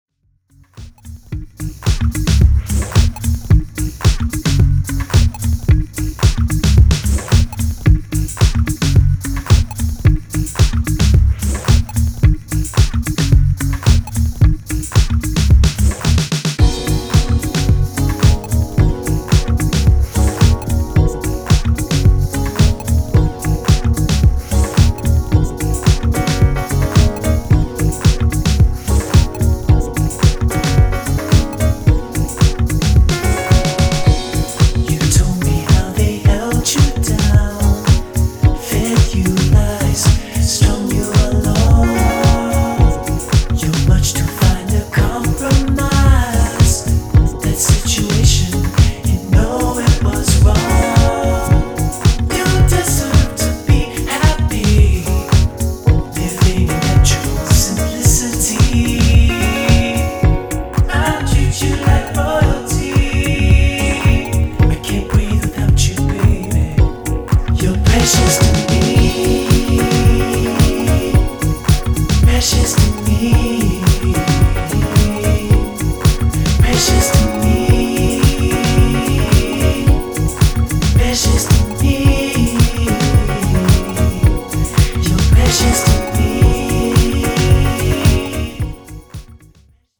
(Vocal)